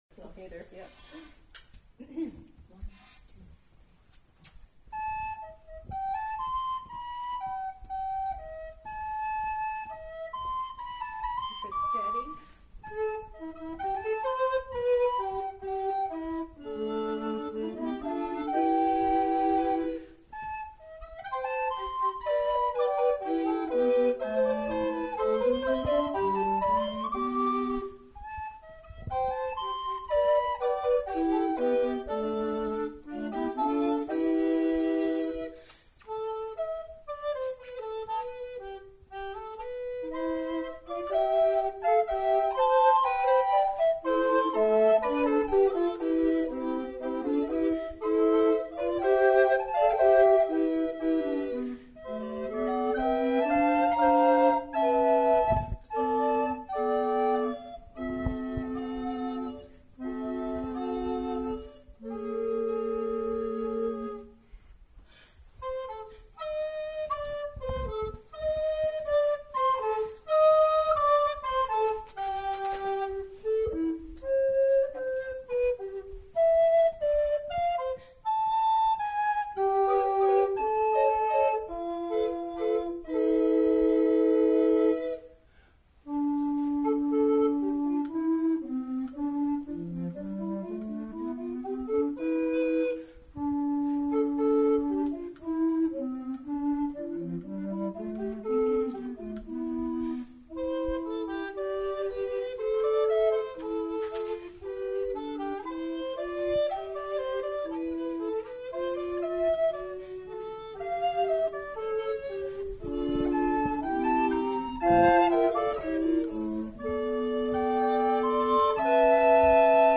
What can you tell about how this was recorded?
The recording above is a rehearsal tape from the afternoon of the concert (Saturday, December 21, 2013) so you can hear a voice or two and some pages turning but I really like the sound of the group in this performance.